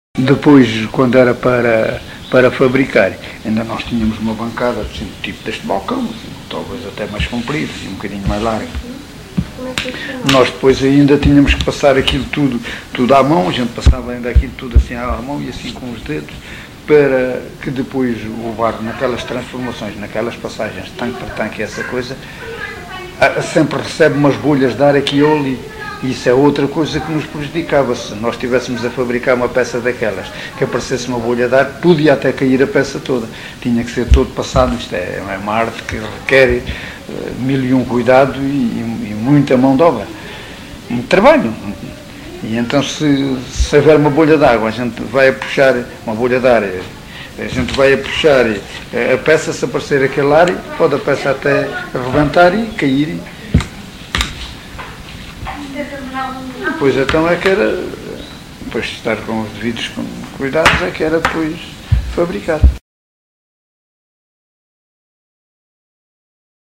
LocalidadeSanta Justa (Coruche, Santarém)